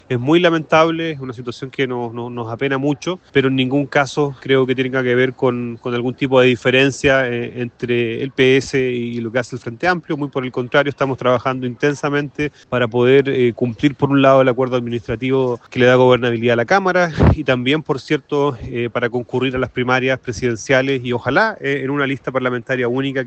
En representación del Frente Amplio, el jefe de bancada en la Cámara de Diputadas y Diputados, Jaime Sáez, lamentó la situación, pero recalcó que su sector buscará mantener la alianza con el Partido Socialista de cara a los próximos comicios presidenciales y parlamentarios.